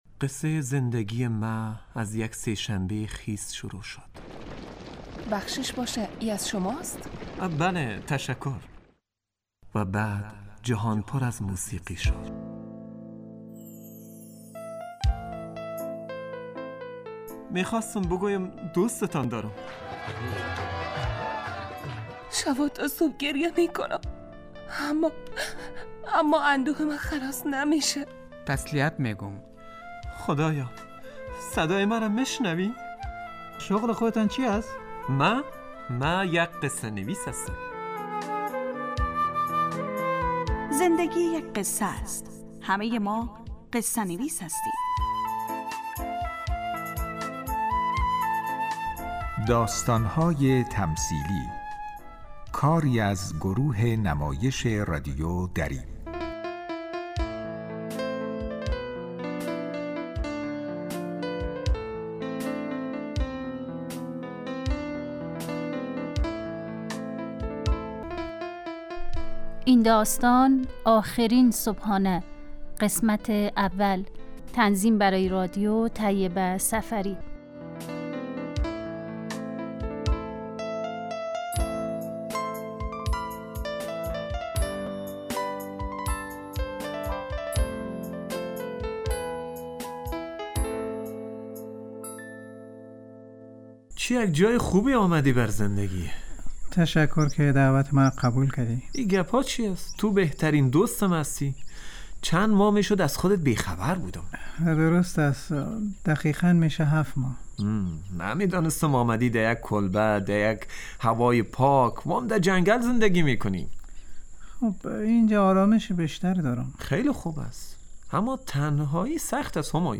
داستانهای تمثیلی نمایش 15 دقیقه ای هستند که روزهای دوشنبه تا پنج شنبه ساعت 03:25عصربه وقت وافغانستان پخش می شود.